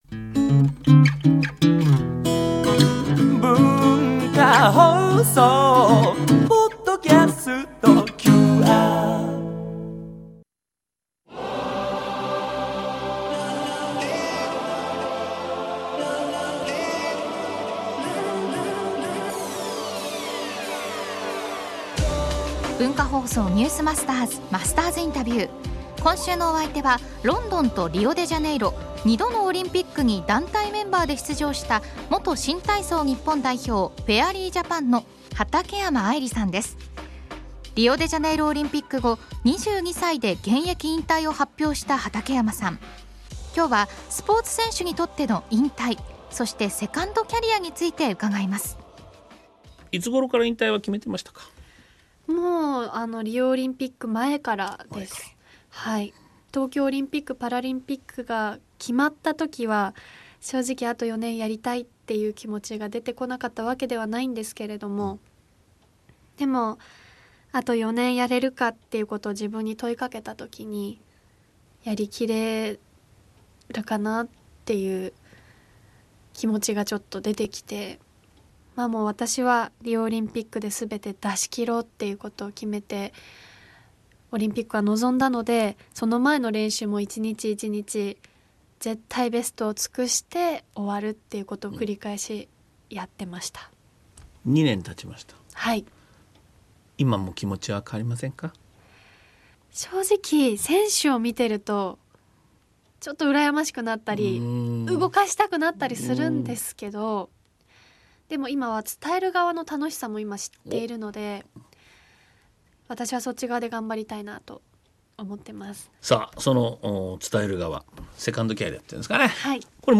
今週のインタビューのお相手は、元新体操日本代表＝フェアリージャパンの畠山愛理さん。
（月）～（金）AM7：00～9：00　文化放送にて生放送！